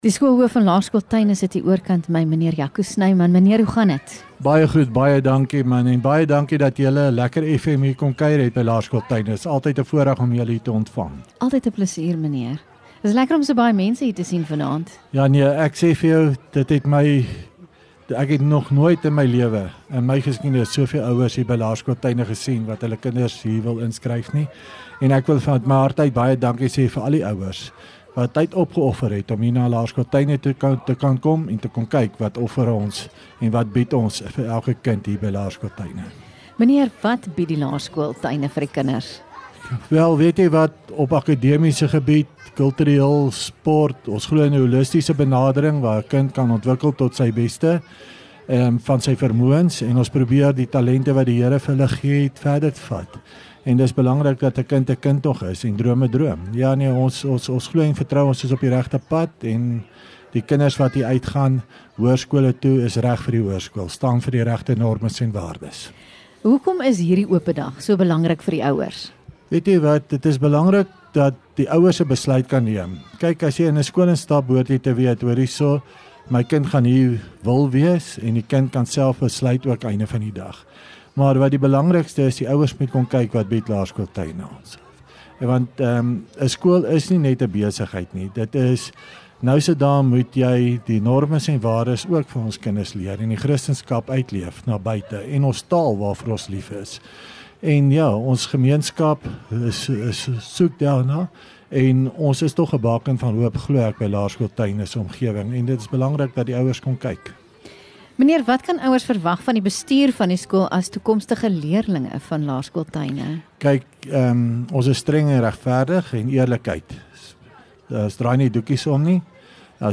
LEKKER FM | Onderhoude 11 Apr Laerskool Tuine